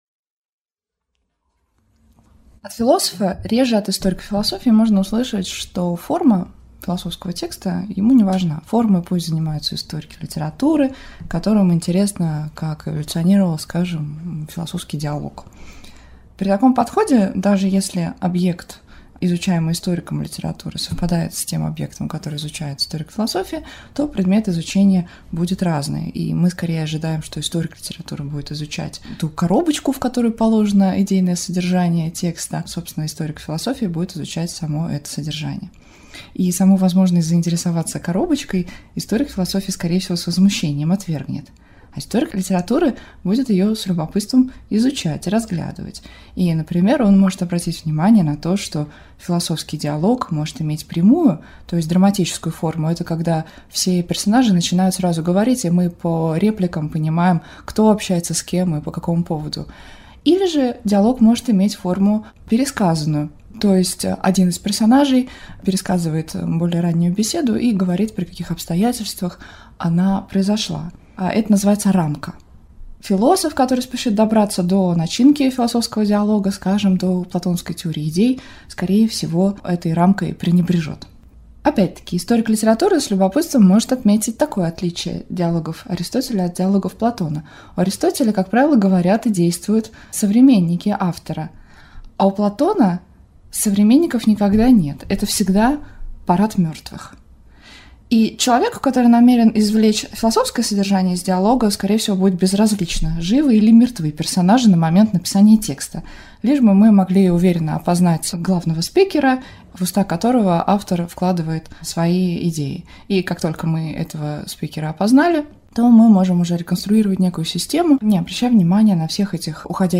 Аудиокнига Форма vs. содержание | Библиотека аудиокниг
Прослушать и бесплатно скачать фрагмент аудиокниги